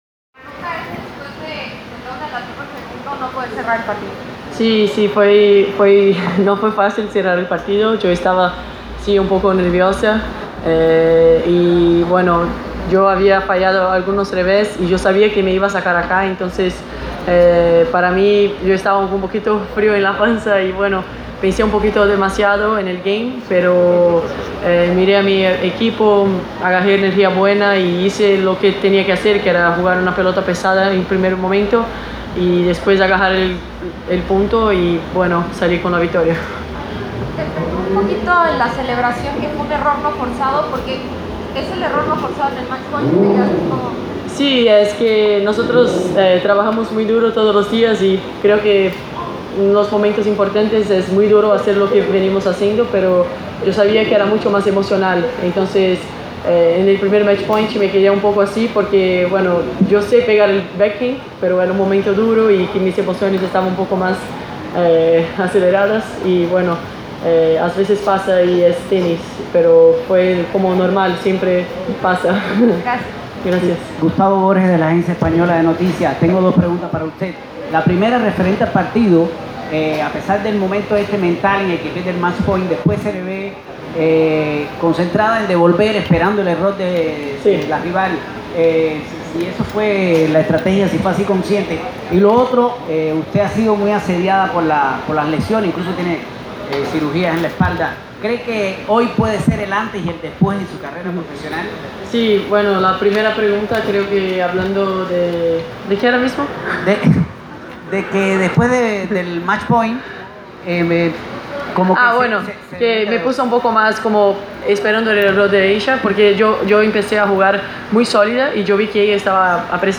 Press Conference – Beatriz Haddad Maia (27/02/2019)